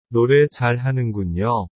ノレ　チャラグンニョ